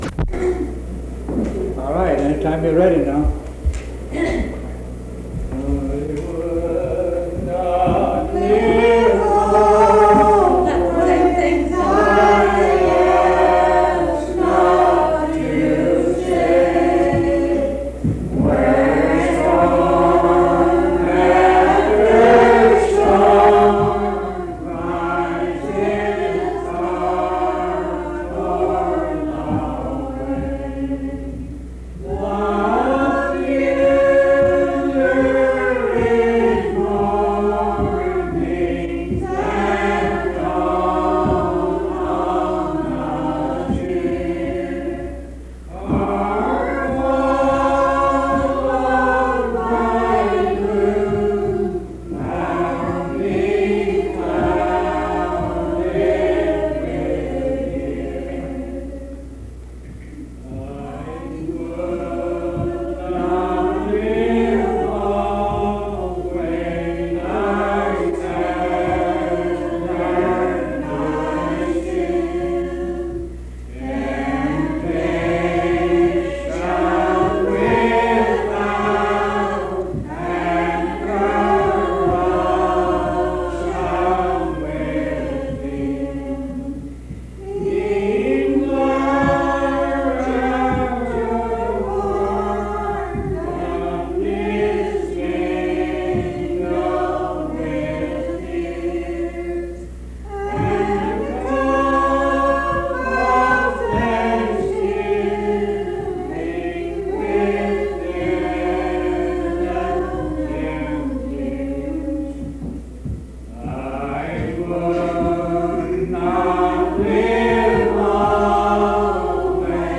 Singing
Hymns Primitive Baptists